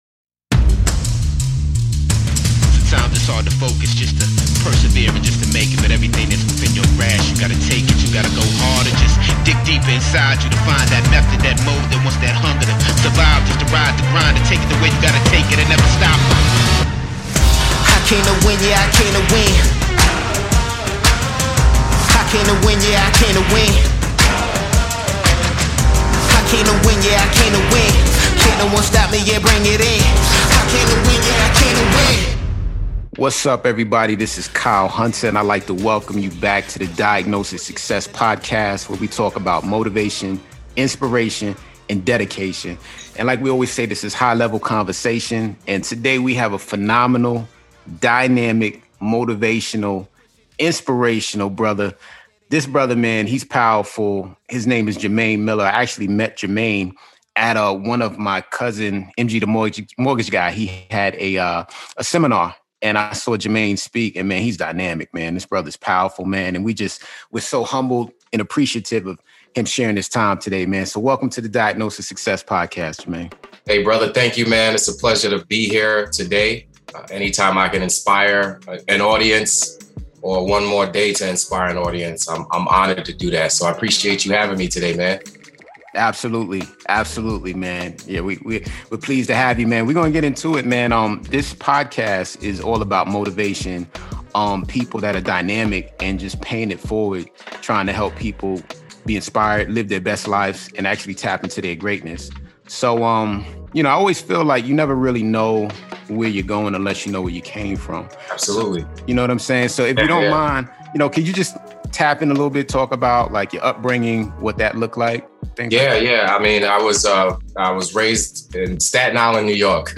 In this episode we interview motivational speaker